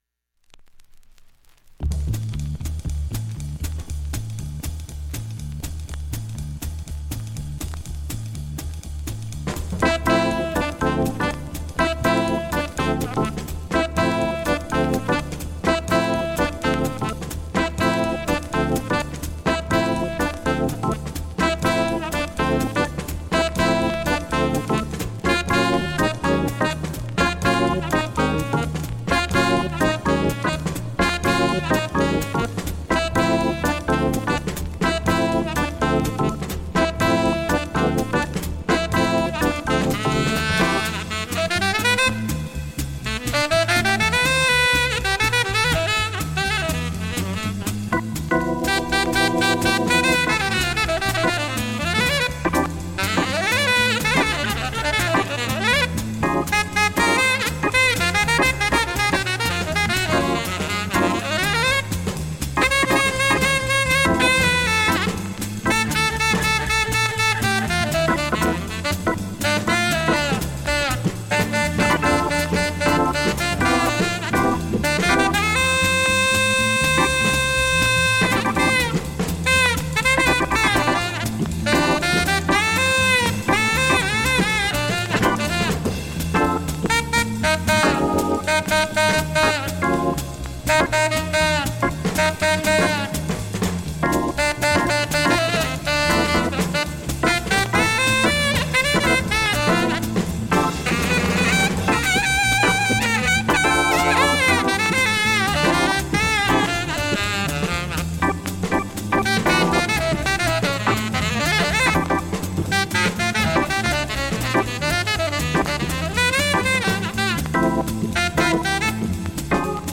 かすかなプツ出ますが、それ以外問題はありません。
音質良好全曲試聴済み
2,(2m27s〜)A-1終わりフェイドアウト部に
かすかなプツが１３回出ます。
３回までのかすかなプツが１箇所
単発のかすかなプツが１箇所
◆ＵＳＡ盤オリジナル Mono